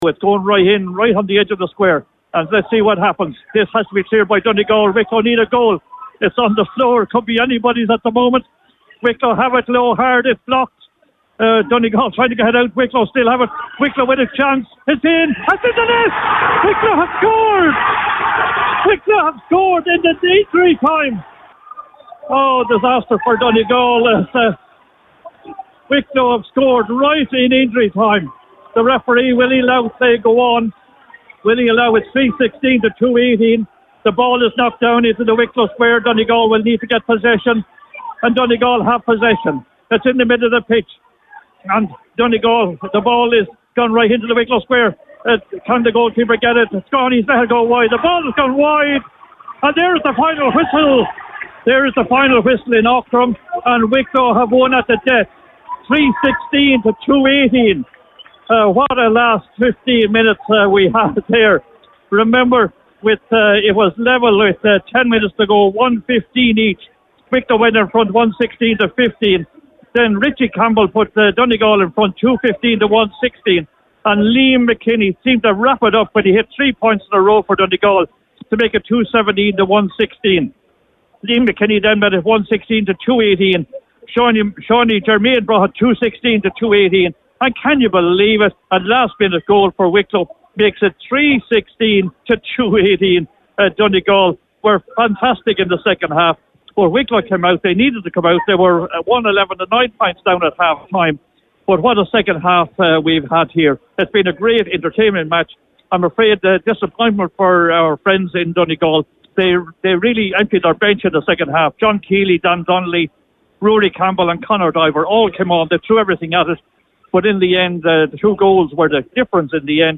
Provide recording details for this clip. was live for Highland Radio Sport as the ball hit the net at the end of the game…